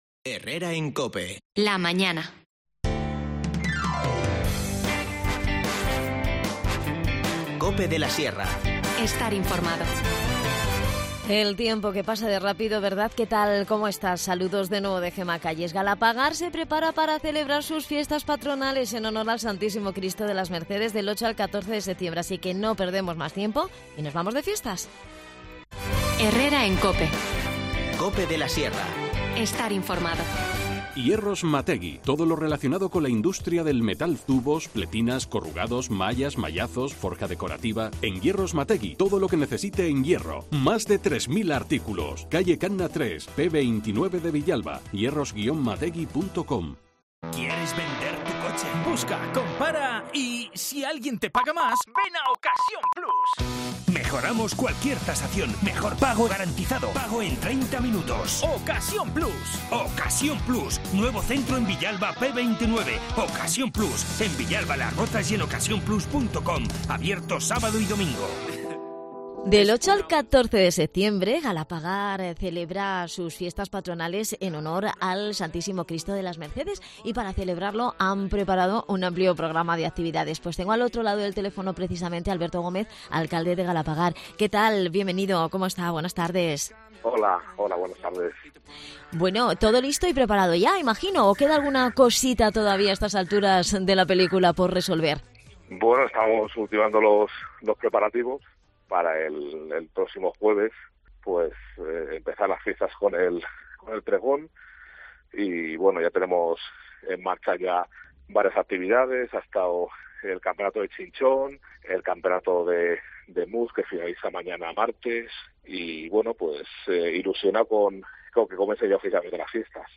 Charlamos sobre la fiestas con Alberto Gómez, alcalde de la localidad.